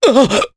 Nicx-Vox_Damage_jp_02.wav